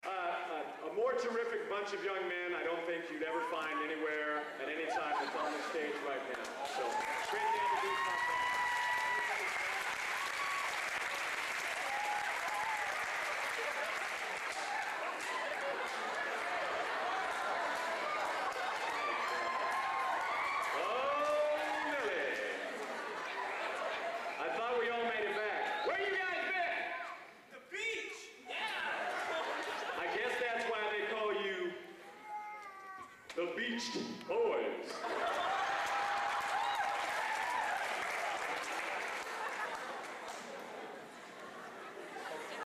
Location: Purdue Memorial Union, West Lafayette, Indiana
Genre: | Type: Director intros, emceeing